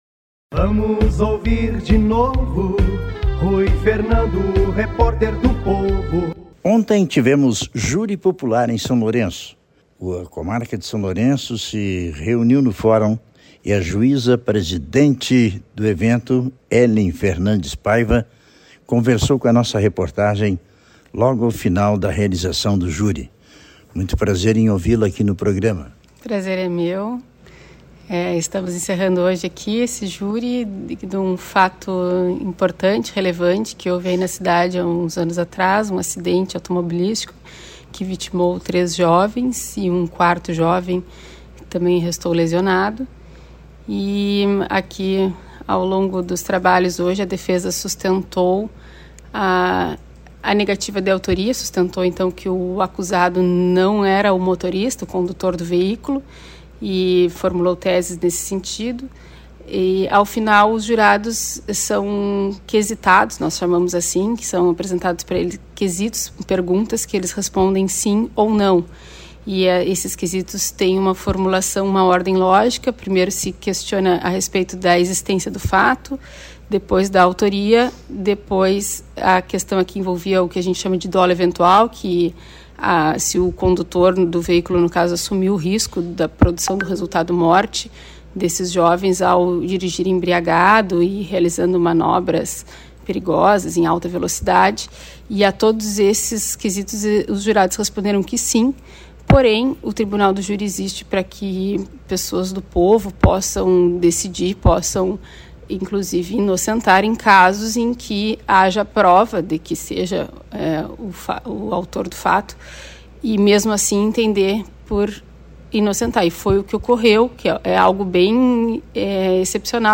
Entrevista com a Juíza Dra. Hélen Fernandes Paiva e com a Promotora de Justiça Dra. Andrelise Borrin Bagatini